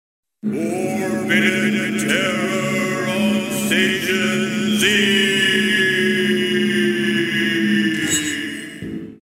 There is another clip from Mangled Metal I'll upload soon, and I'll probably capture some video from the game for both clips, since the voiceover works a lot better in the context of the video.